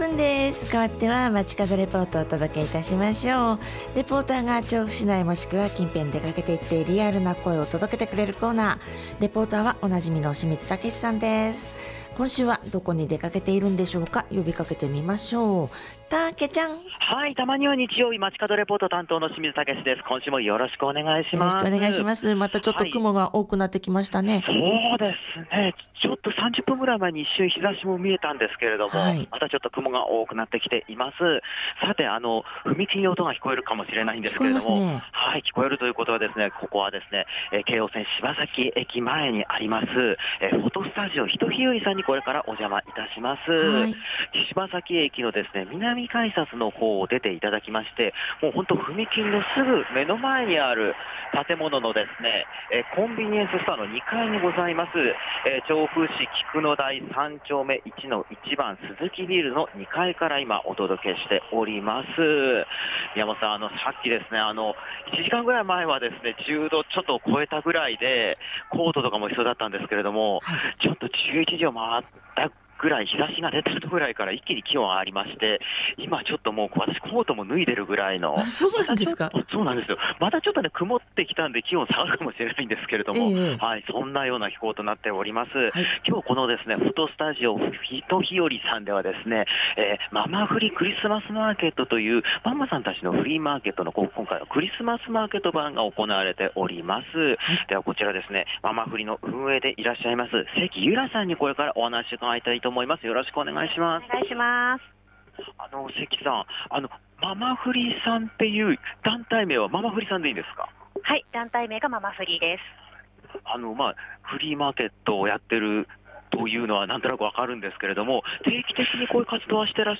ぐずついたお天気の下からお届けした、本日の街角レポートは
フォトスタジオひとひよりさんで開催中の『ママフリ-クリスマスマーケット-』からのレポートでした。